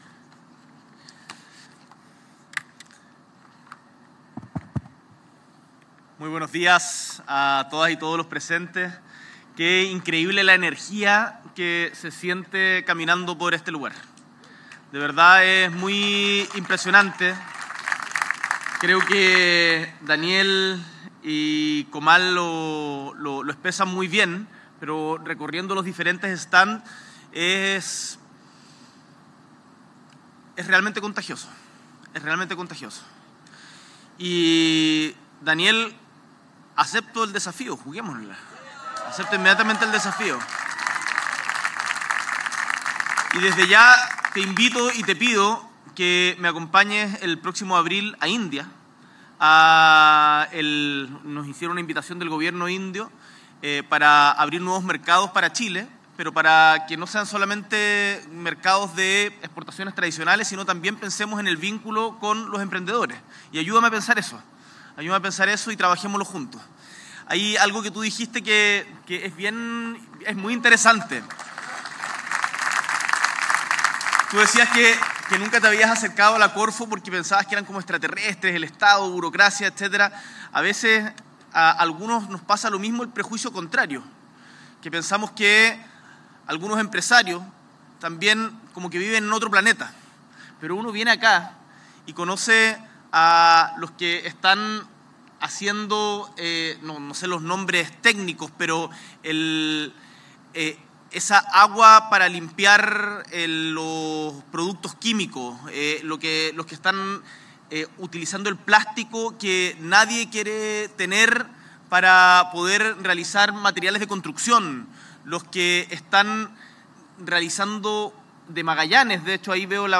S.E. el Presidente de la República, Gabriel Boric Font, participa de Emprende Tu Mente Day 2024